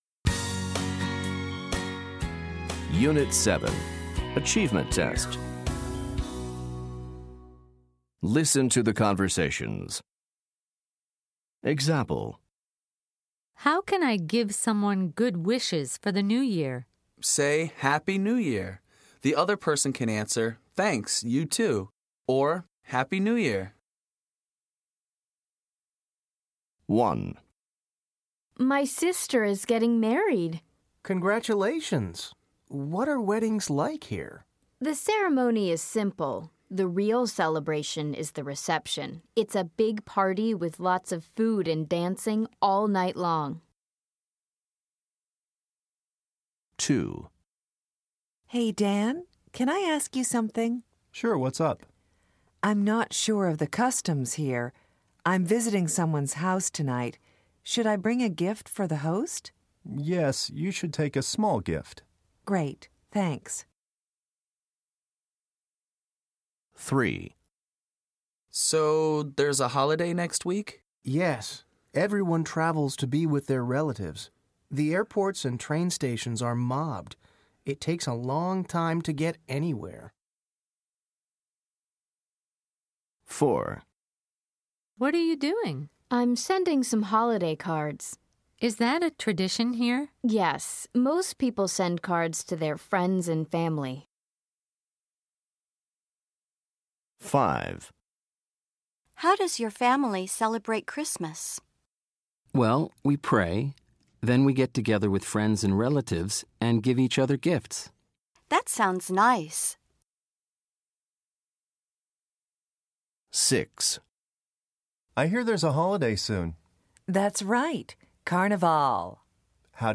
Listen to the conversations. Then match each conversation with the correct picture.